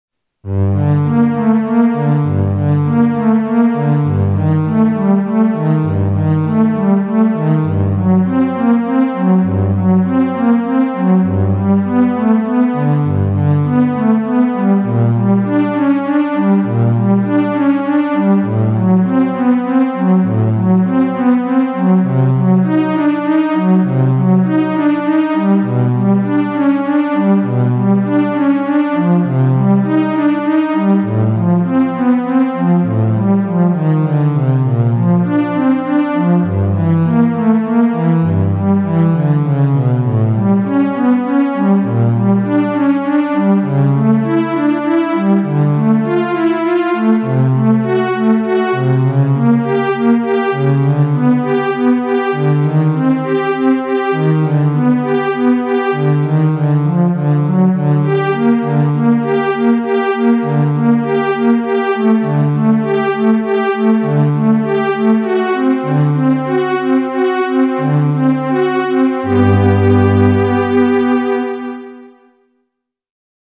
Prelude, for solo cello, viola, or violin, based on Bach's name
In order somehow to innovate, the arrangement is based on a ternary rhythm instead of the binary one in Bach's prelude.